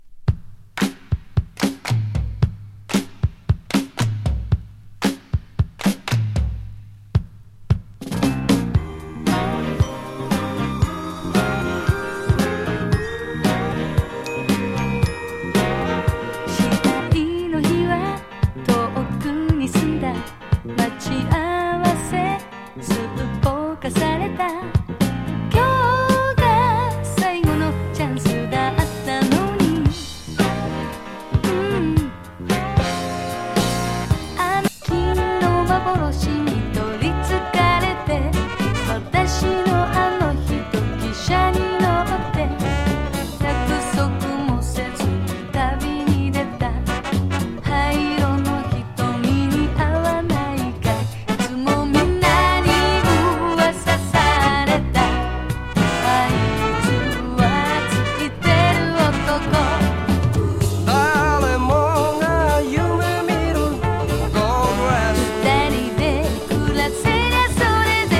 ウェストコースティン・シティポップ